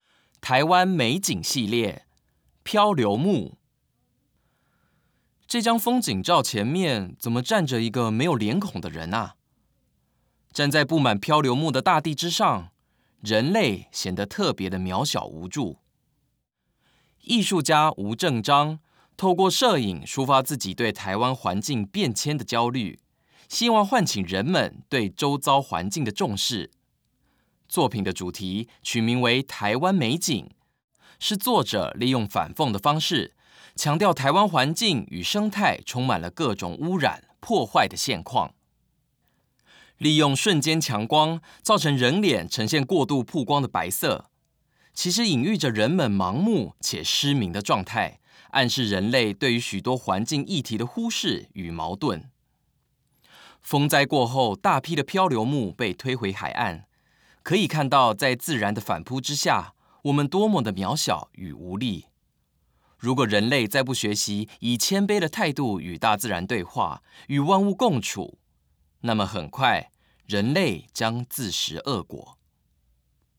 語音導覽